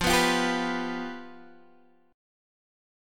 Fsus2b5 chord